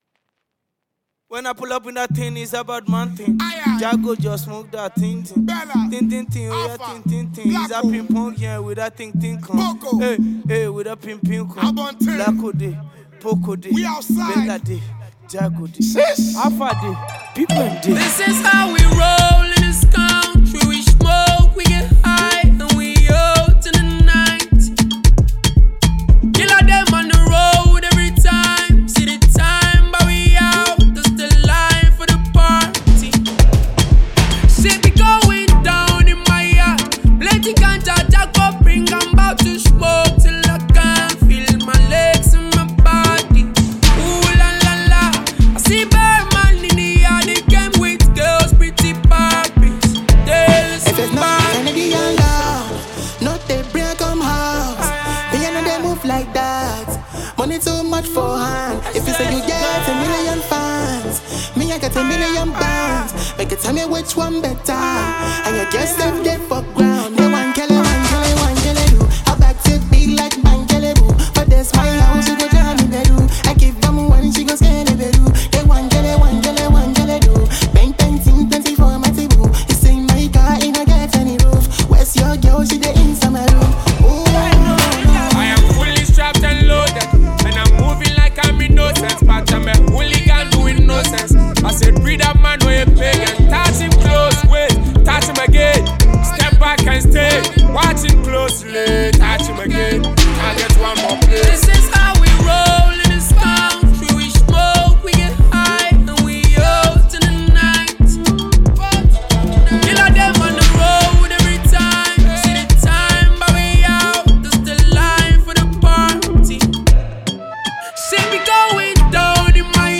Music Nigeria Music
catchy single